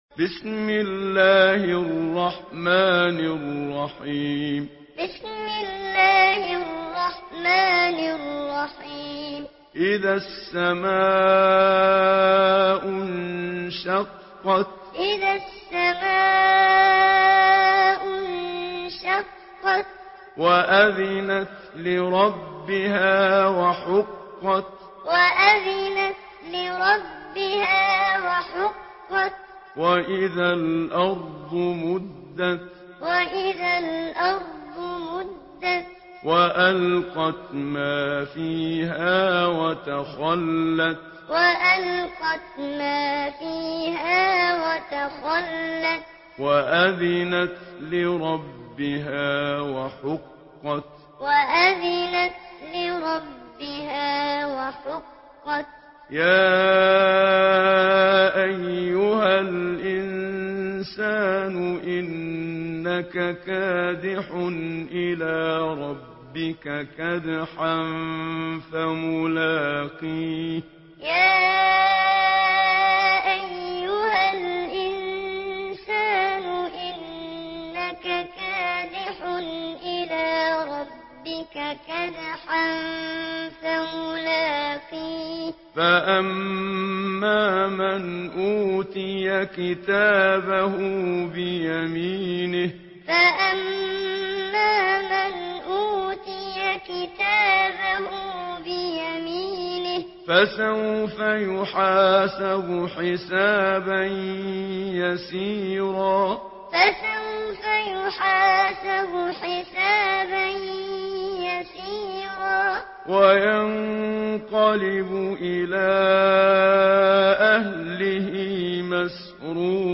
Surah Al-Inshiqaq MP3 in the Voice of Muhammad Siddiq Minshawi Muallim in Hafs Narration
Surah Al-Inshiqaq MP3 by Muhammad Siddiq Minshawi Muallim in Hafs An Asim narration.